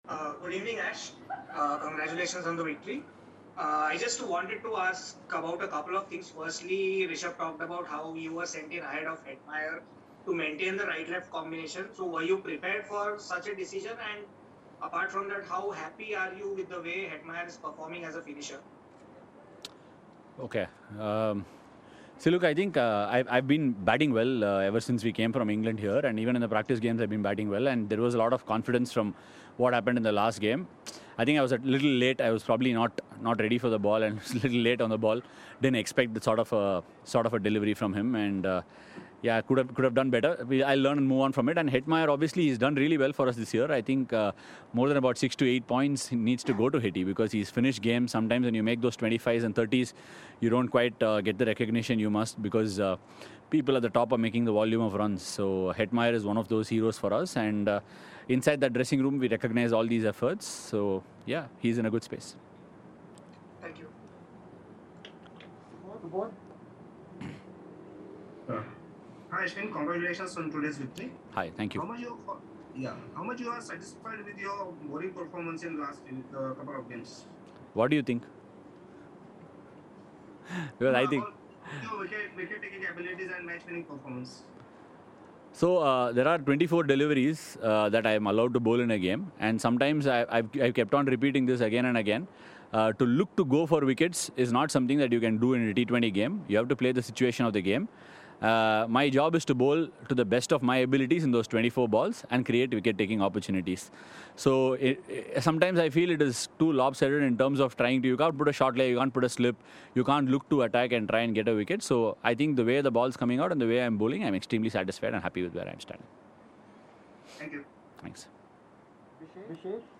R Ashwin of Delhi Capitals addressed the media at the end of the game